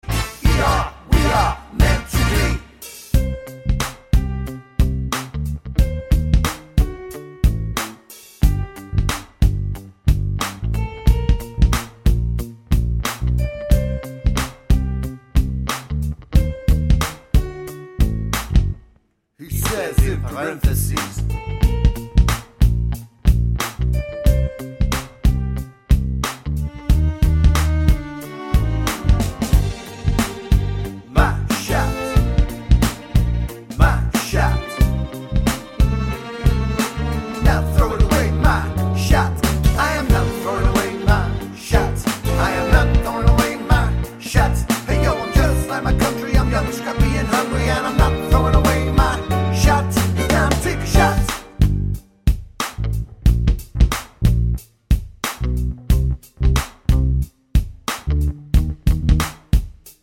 no Backing Vocals Musicals 5:40 Buy £1.50